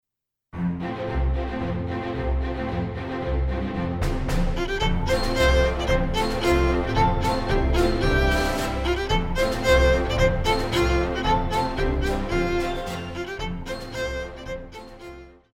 小提琴
管弦樂團
童謠,經典曲目,傳統歌曲／民謠,古典音樂
獨奏與伴奏
有主奏
有節拍器